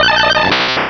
Cri de Triopikeur dans Pokémon Rubis et Saphir.